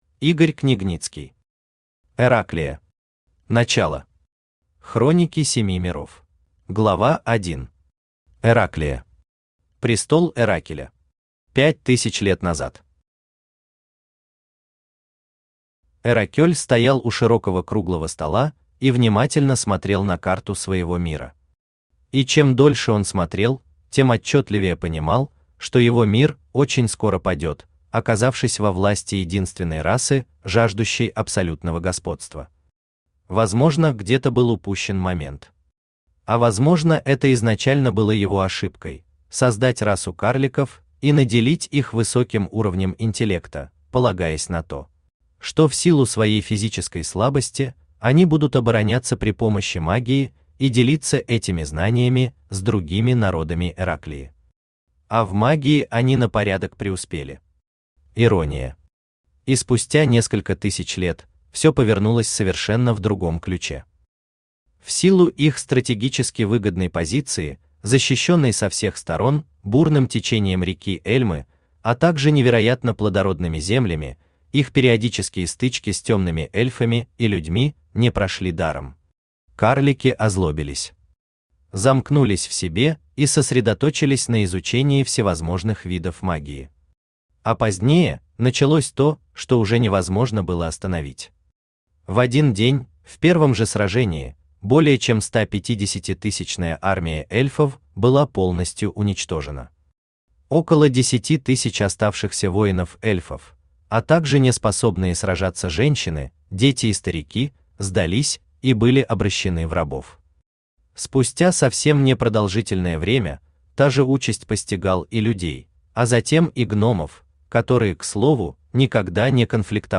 Аудиокнига Эраклия. Начало. Хроники Семи Миров | Библиотека аудиокниг
Читает аудиокнигу Авточтец ЛитРес.